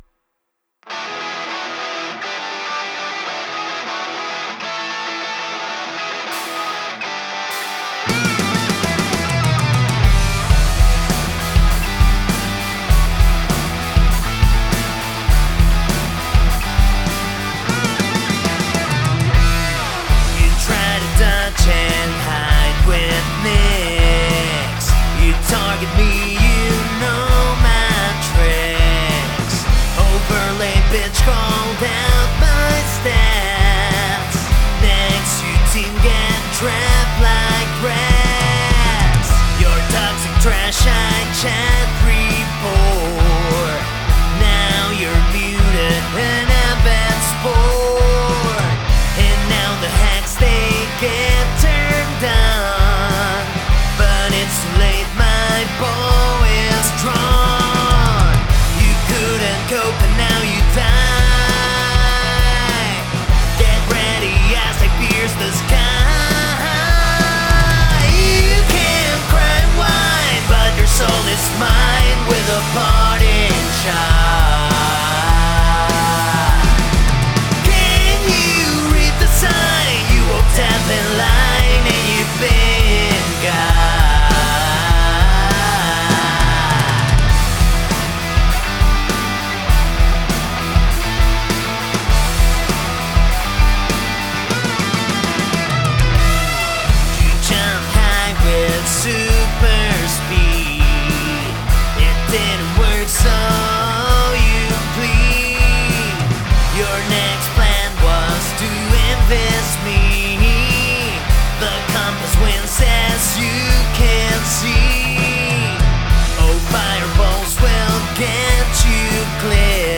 I dig the solo.
Vocals are definitely in style.